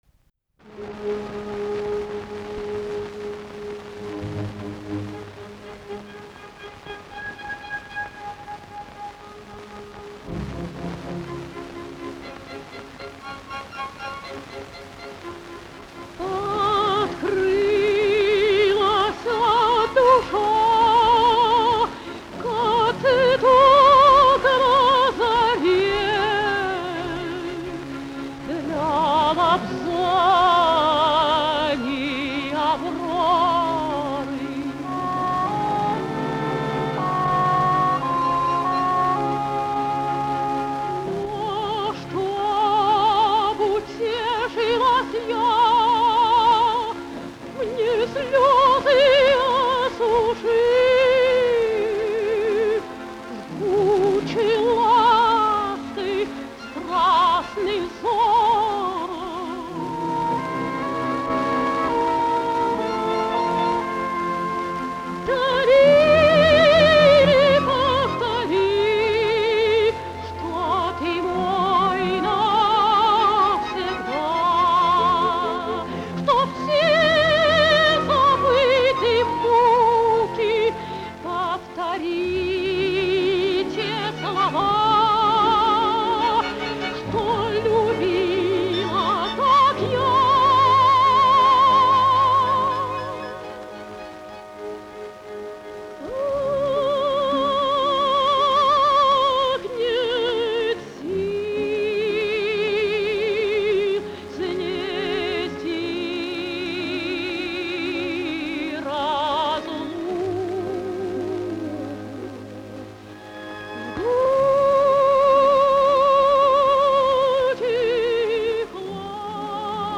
14 - Надежда Обухова - Ария Далилы (К.Сен-Санс. Самсон и Далила, 2 д.).mp3